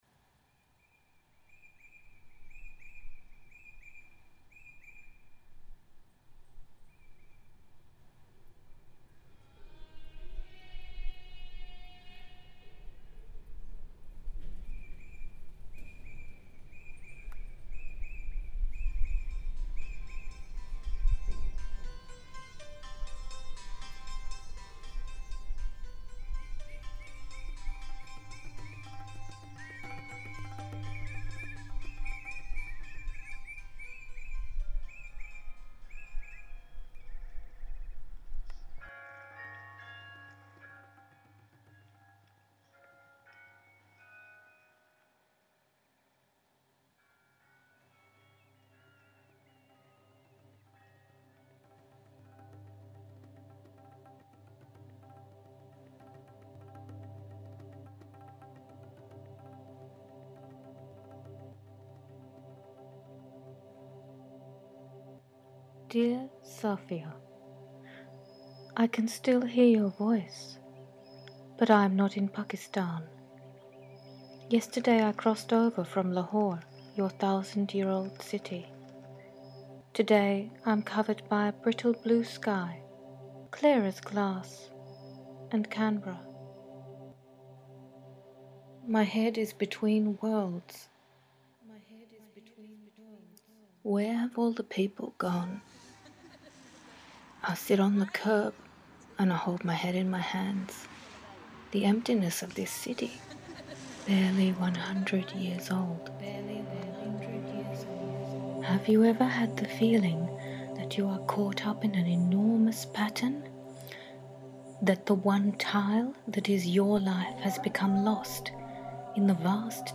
I recorded conversations with some of these, including my own family.